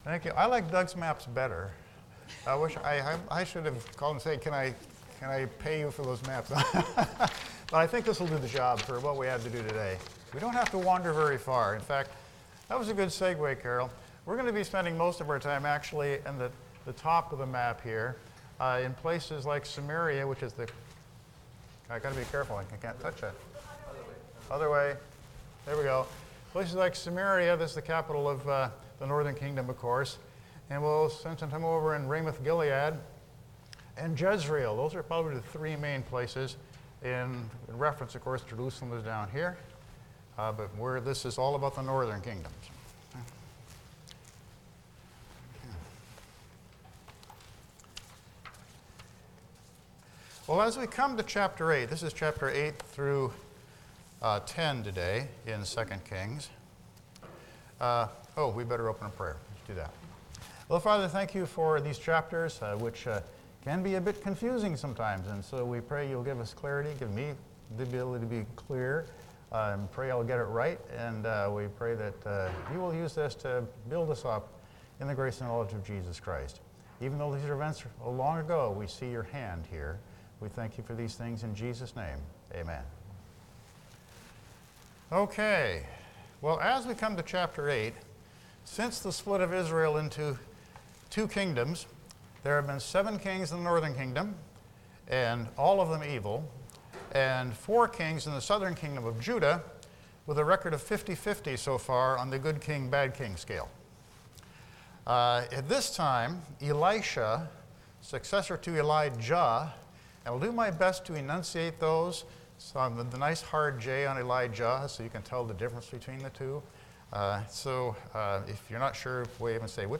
Passage: 2 Kings 8-10 Service Type: Sunday School